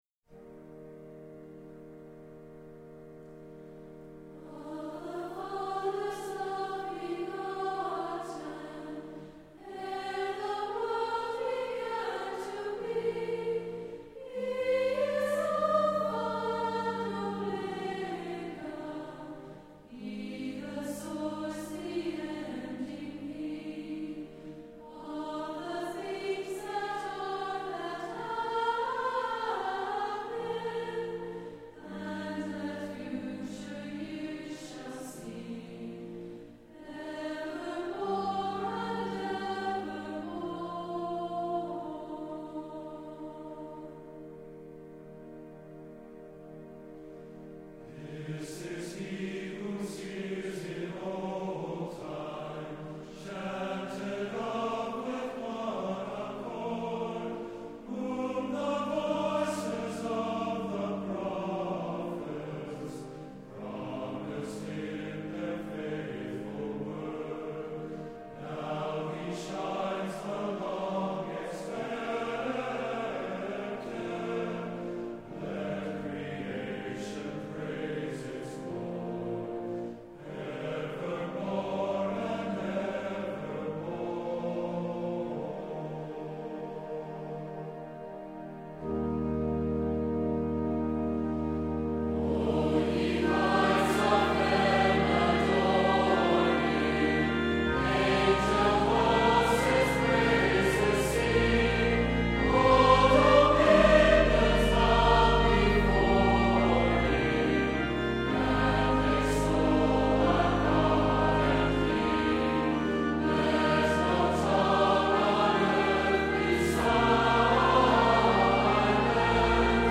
Recorded October 21, 1995 at the Provo Utah Central Stake center, the Choir for the 1995 Christmas concert numbers approximately eighty singers with most of the stake's twelve wards represented.